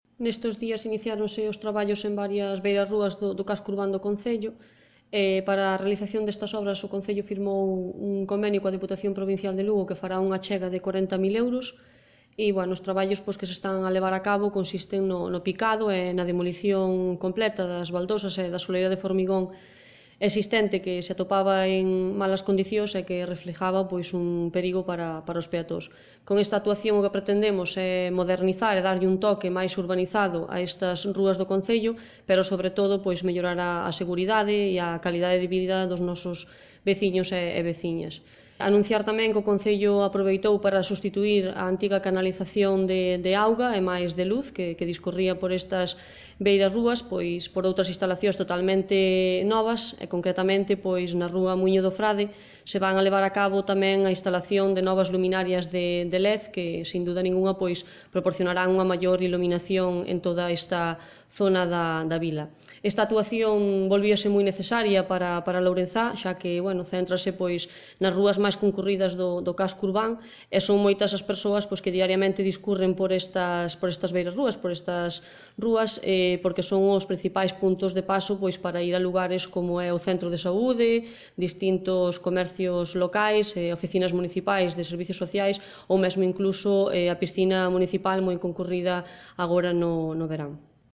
Adjunto Tamaño Escuchar declaraciones Alcaldesa 1.39 MB